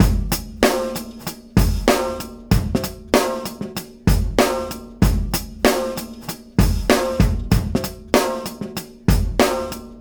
Track 14 - Drum Break 03.wav